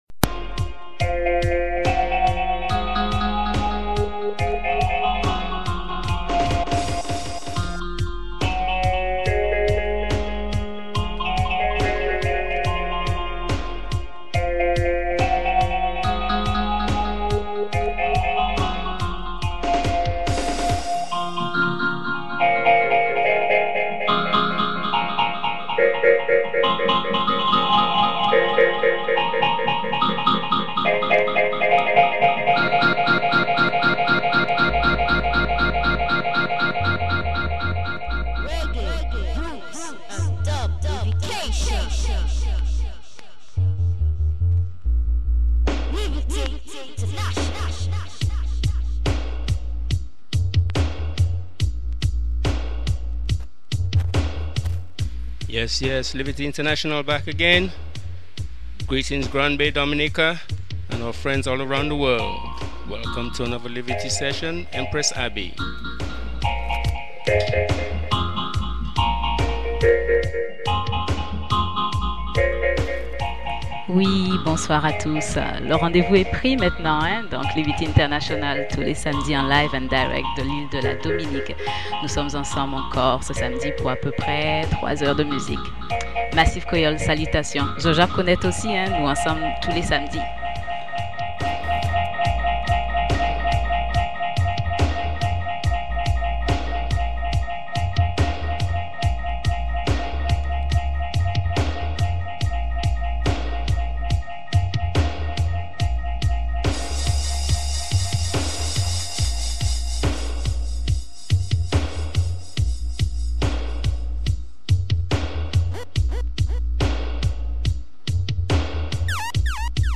Live & Direct.